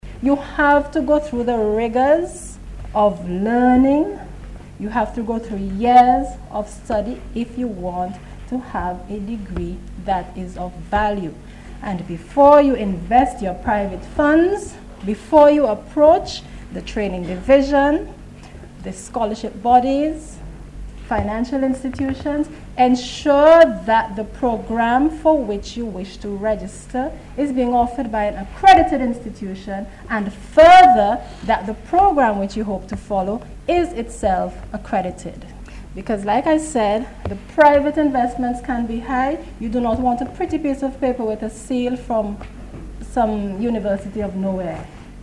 Miss Gilchrist made the appeal, during her remarks at the start of a one-day Symposium held at the NIS Conference Room on Tuesday.